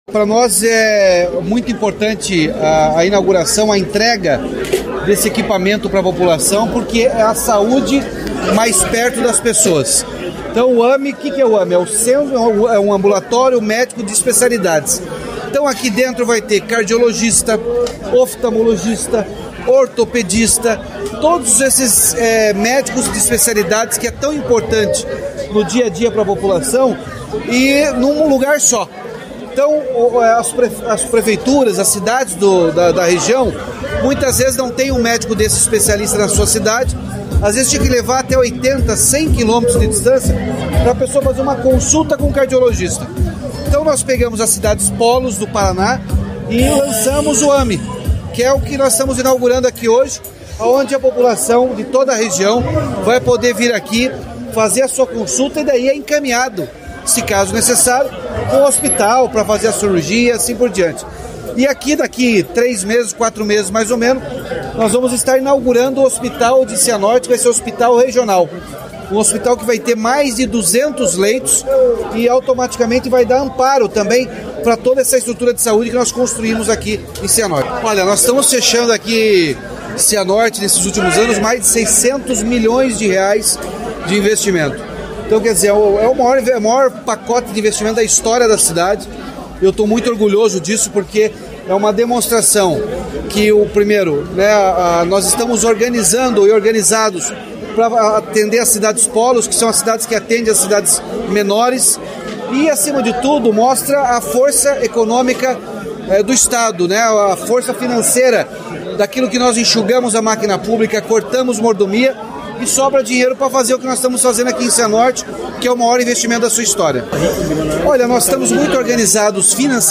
Sonora do governador Ratinho Junior sobre a inauguração do Ambulatório Médico de Especialidades em Cianorte